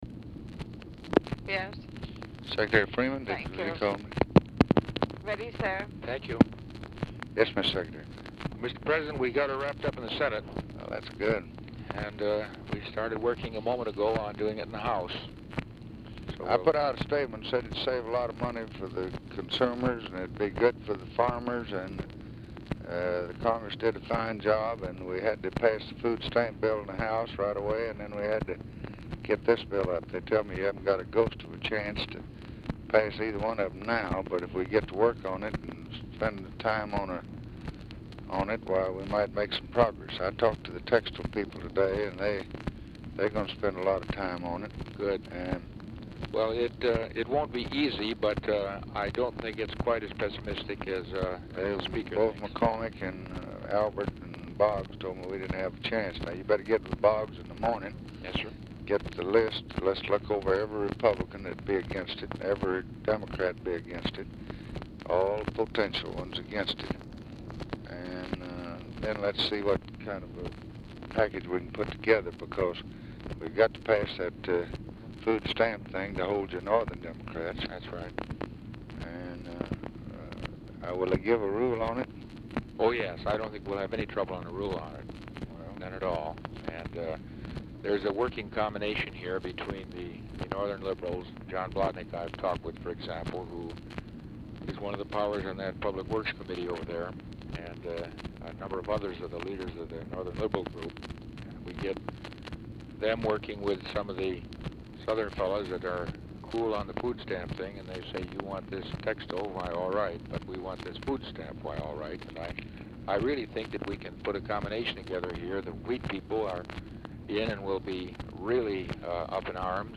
Telephone conversation # 2374, sound recording, LBJ and ORVILLE FREEMAN, 3/6/1964, 8:08PM | Discover LBJ
Format Dictation belt
Location Of Speaker 1 Oval Office or unknown location